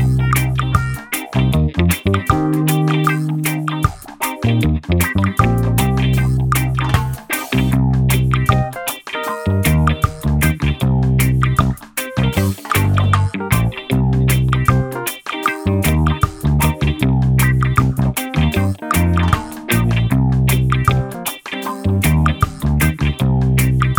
minus wahwah guitar no Backing Vocals Reggae 3:16 Buy £1.50